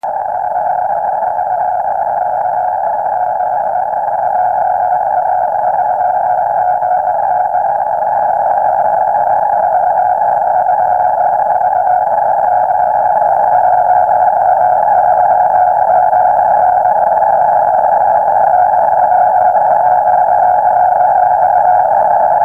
こちらのアンテナは前回と同じ、2階のベランダに低く上げたスモールループです。
こちらが受信した信号の様子です。Argoも併用するつもり(結局使わず)でしたので帯域が少し広いままですが、前回より良く聞こえているのがお分かりいただけると思います。